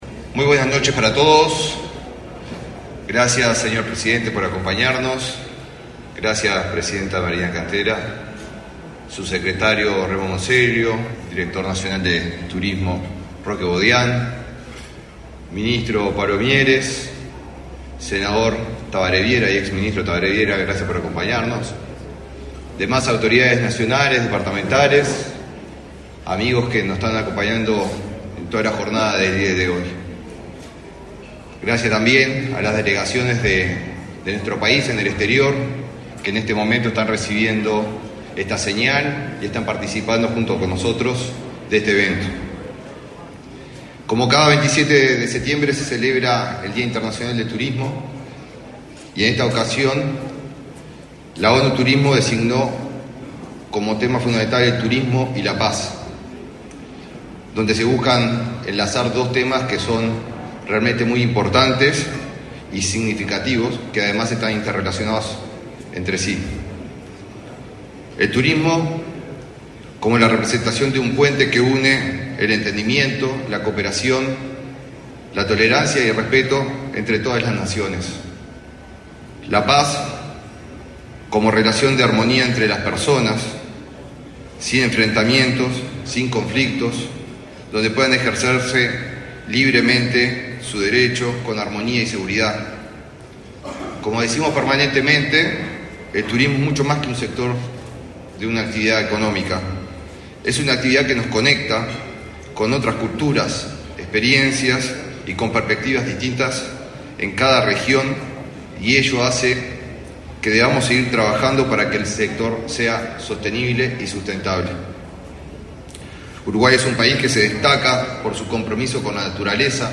Palabras del ministro de Turismo, Eduardo Sanguinetti
Este 27 de setiembre se realizó la conmemoración del Día Munidal del Turismo, con la presencia del presidente de la República, Luis Lacalle Pou.
Disertó en el evento el ministro de Turismo, Eduardo Sanguinetti.